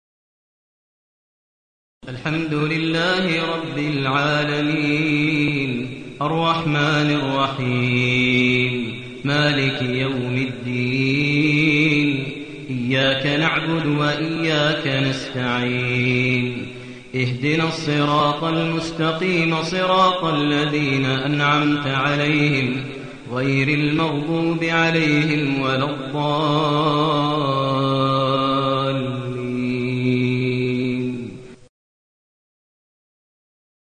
المكان: المسجد النبوي الشيخ: فضيلة الشيخ ماهر المعيقلي فضيلة الشيخ ماهر المعيقلي الفاتحة